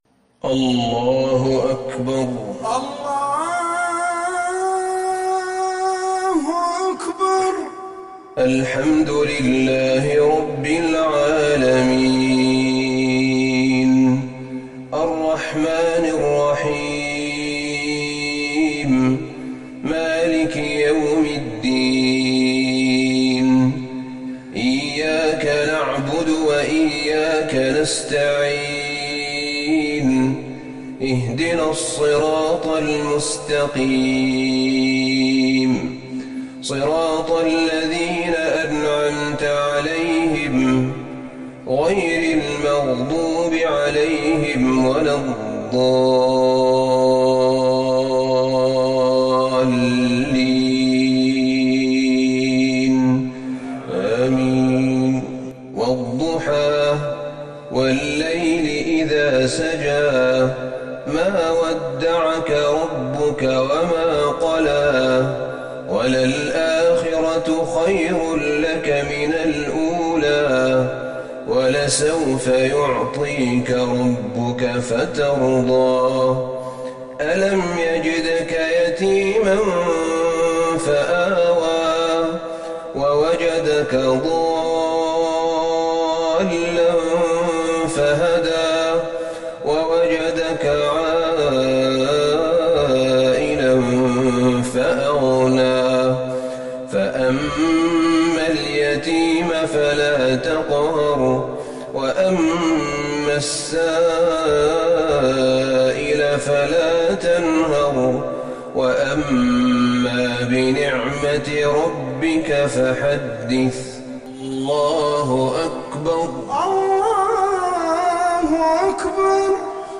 صلاة المغرب للشيخ أحمد بن طالب حميد 13 رجب 1441 هـ
تِلَاوَات الْحَرَمَيْن .